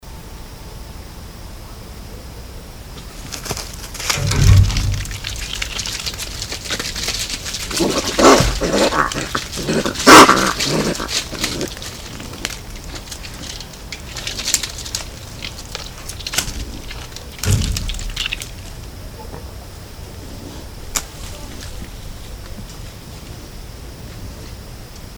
Звуки енота
Звук разозленного енота